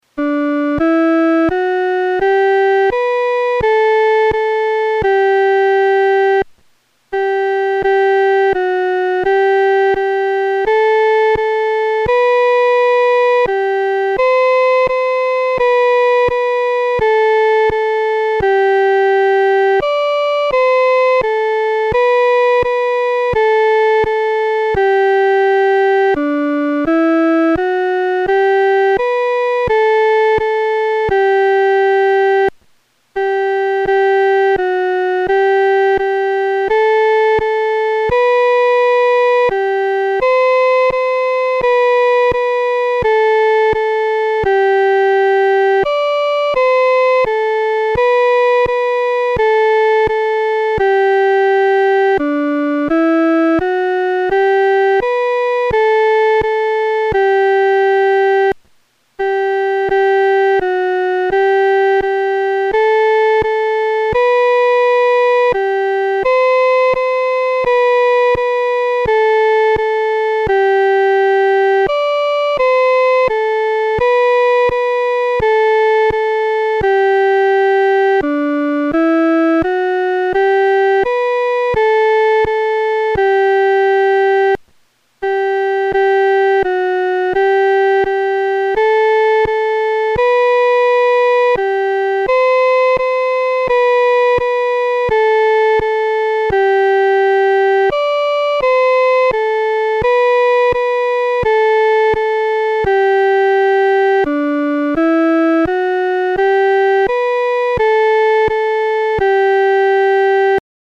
女高
这首诗歌充满着虔敬和恳切，我们在弹唱时的速度不宜太快。